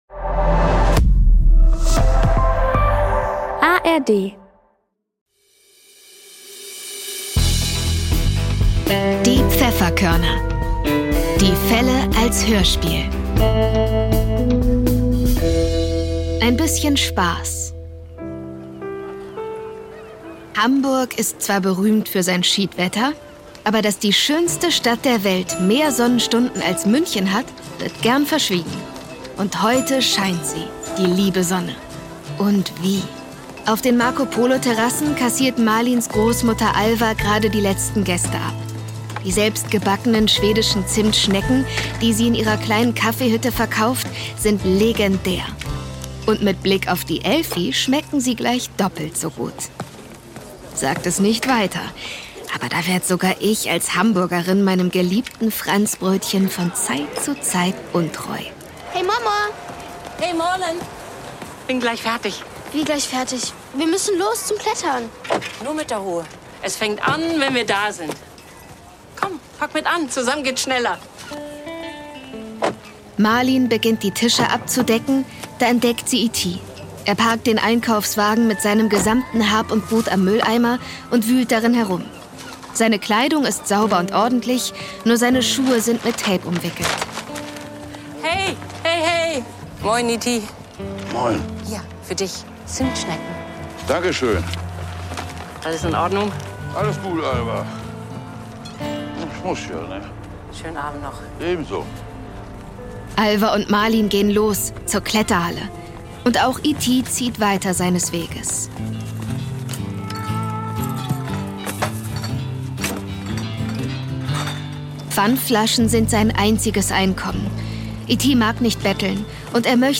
Ein bisschen Spaß (3/21) ~ Die Pfefferkörner - Die Fälle als Hörspiel Podcast